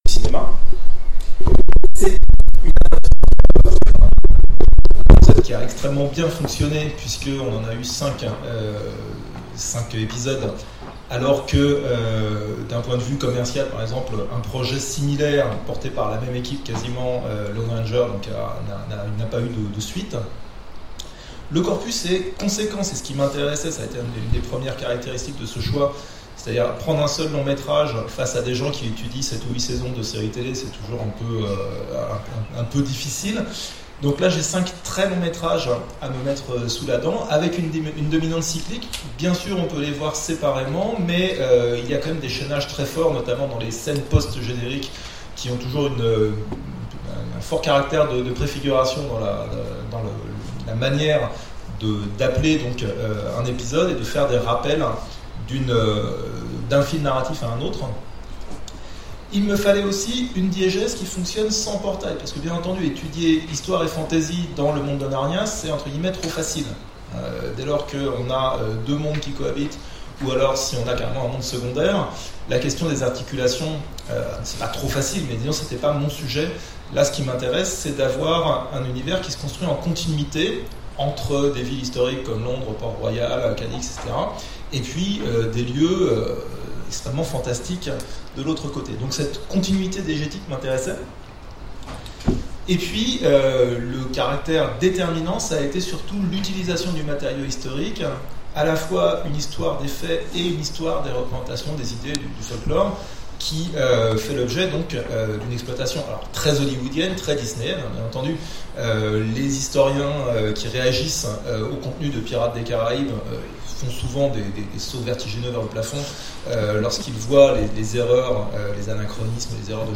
Colloque universitaire 2018 : Fantasy au cinéma, jusqu’où peut aller la promesse d'historicité ?
Mots-clés Cinéma Conférence Partager cet article